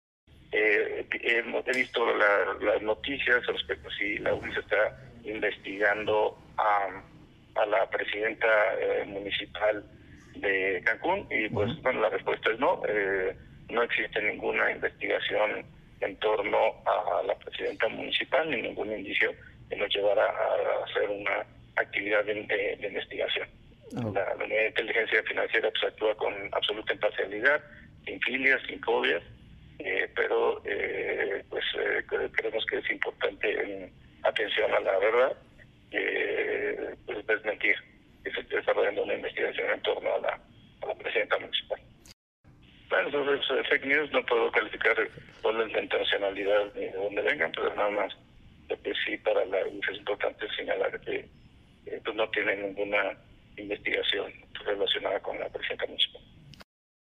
Santiago Nieto, titular de la Unidad de Inteligencia Financiera, en entrevista para Novedades